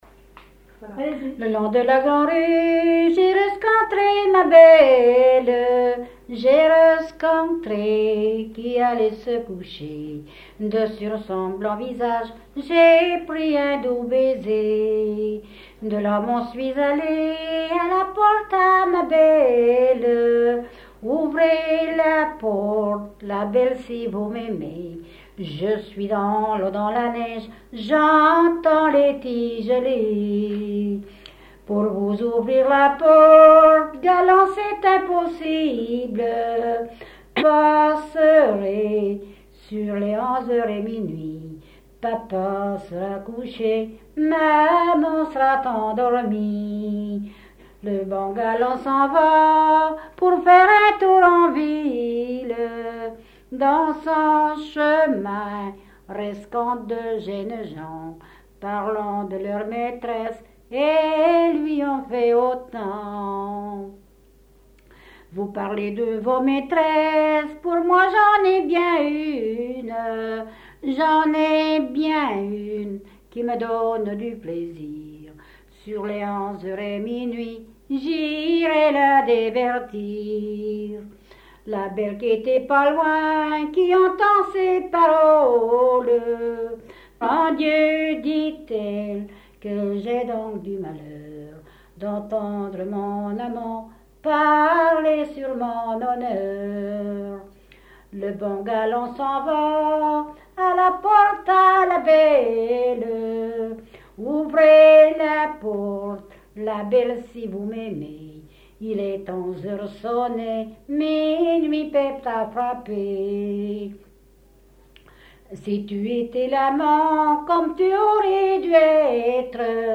Localisation Barbâtre (Plus d'informations sur Wikipedia)
Genre strophique
Catégorie Pièce musicale inédite